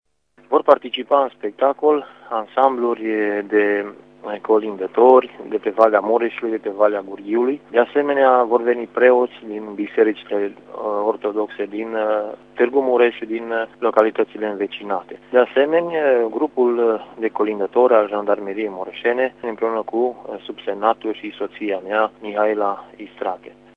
Vor mai participa şi ansambluri de colindători de pe văile Mureşului şi a Gurghiului, a precizat interpretul de muzică populară